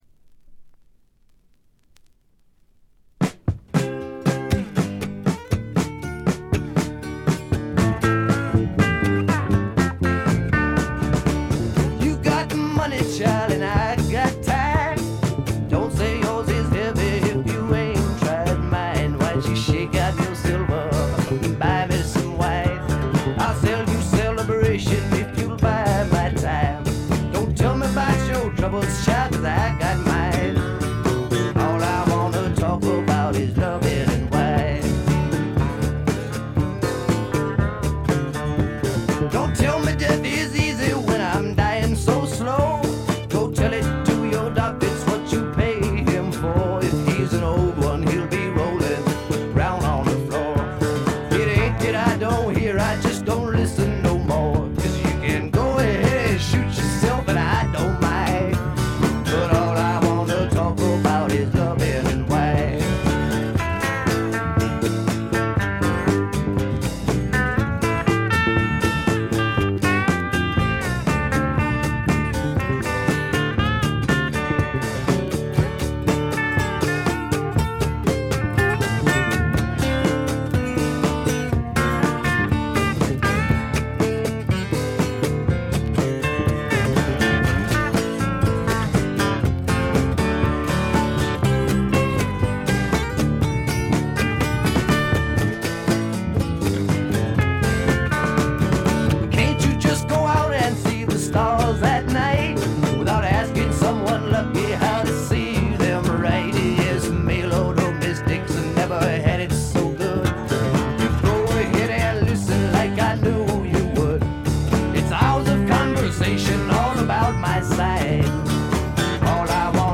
バックグラウンドノイズ、チリプチ多め大きめです。
試聴曲は現品からの取り込み音源です。